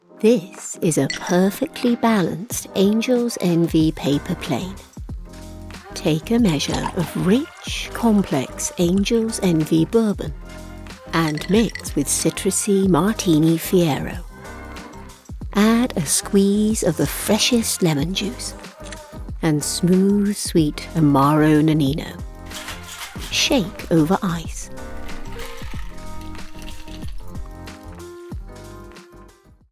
An experienced British English voice actor with a warm assured voice and versatility
Smooth refined RP Commercial